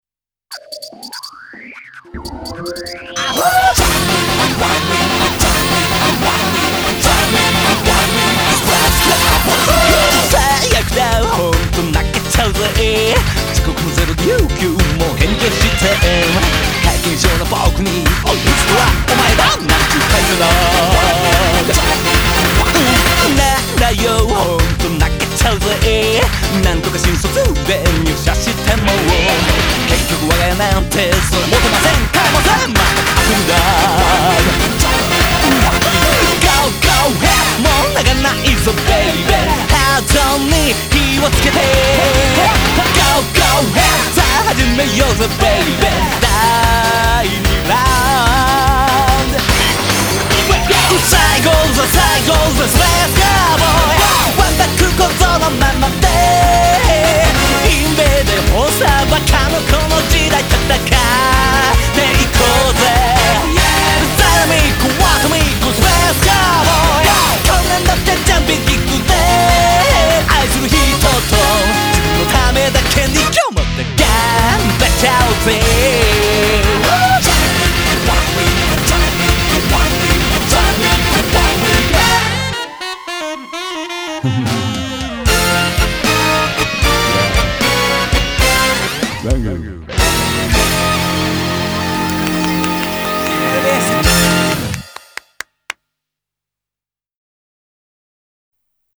BPM147-154
Audio QualityPerfect (High Quality)
Genre: CYBER ROCKABILLY.